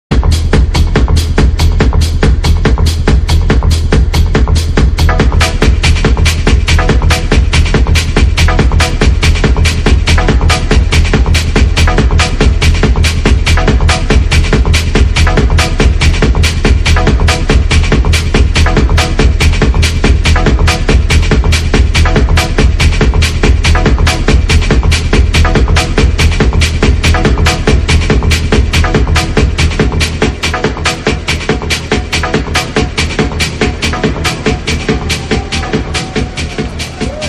This may be difficult since it was the last track of a set that was cut off.